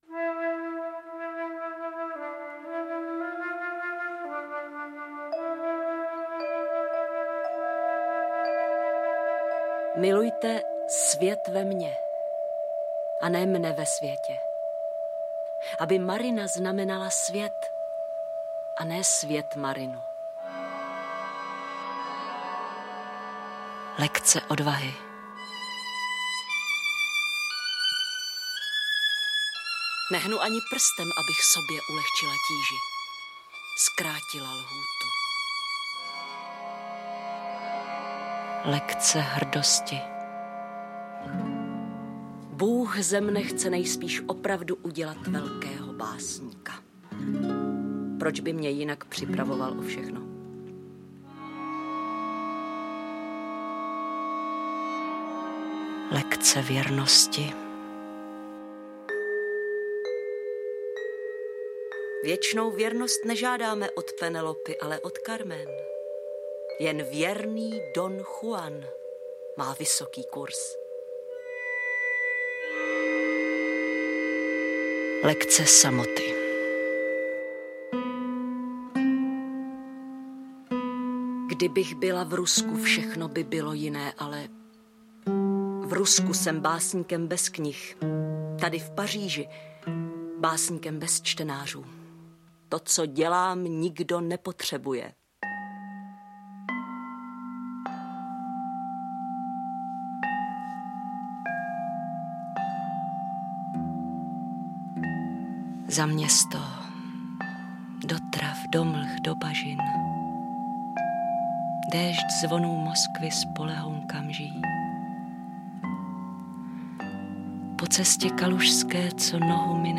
Interpret:  Hana Kofránková
AudioKniha ke stažení, 1 x mp3, délka 40 min., velikost 36,5 MB, česky